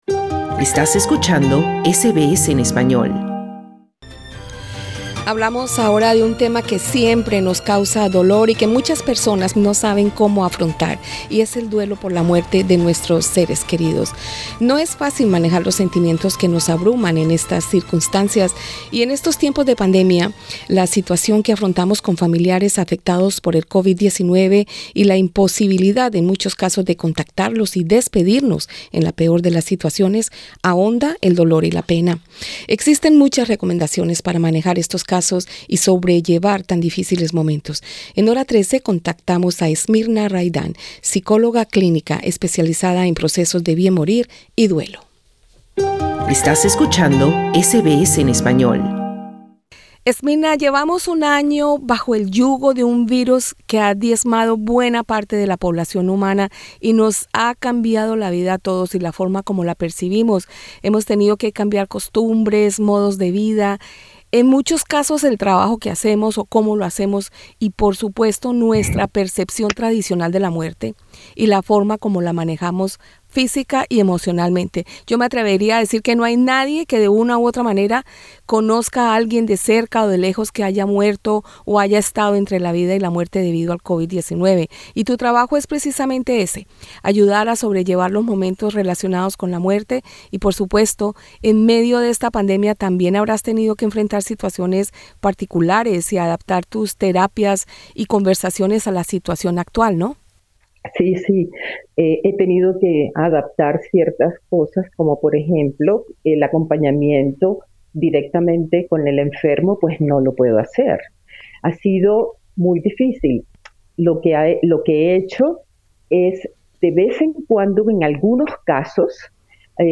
En SBS Spanish hablamos con